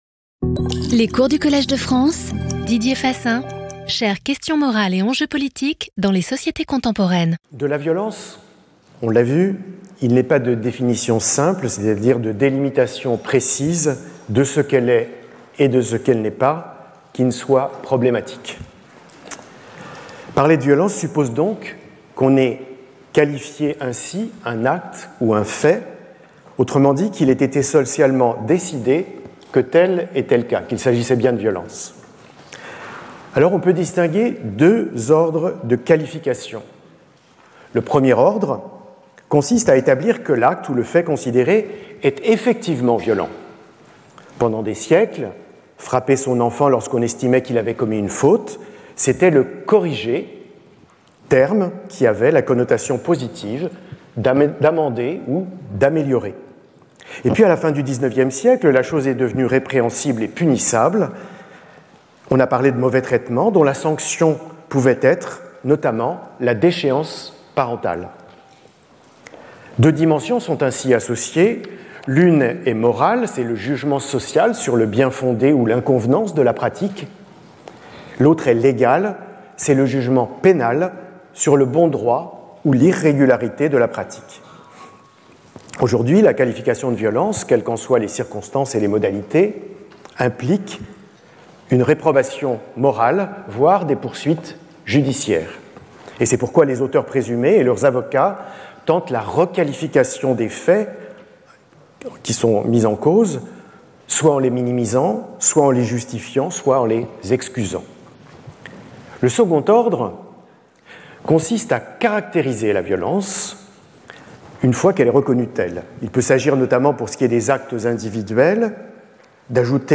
Didier Fassin Professeur du Collège de France
Cours